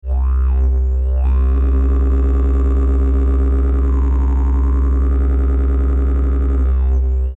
donc je suis parti sur une note de base du didge en Do, puis j'ai monté la gamme en vocalises dedans, demi-ton par demi-ton.....
Do#
je vous laisse entendre les différents frottements que ça donne.....bon je chante pas non plus super juste, donc des fois ça fluctue un peu, mais ça devrait suffir pour se faire une idée... :mrgreen: